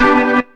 B3 GMAJ 1.wav